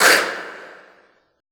Index of /90_sSampleCDs/Best Service - Extended Classical Choir/Partition I/CONSONANTS